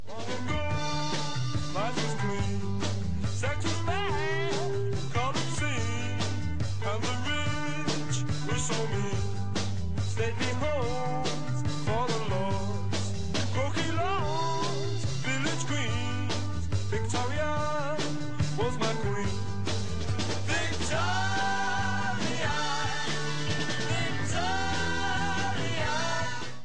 reduced quality